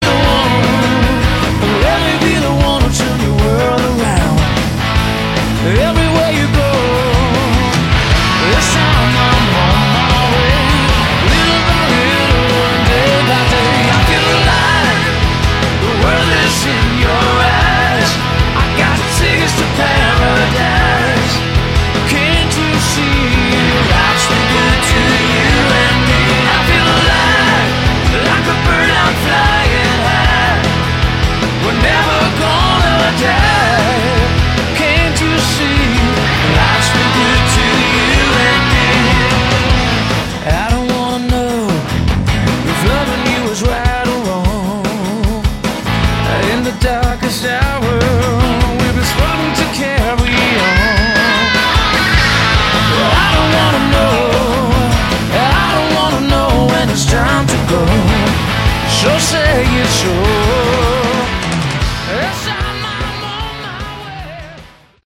Category: Hard Rock
vocals, guitar, keyboards
bass
drums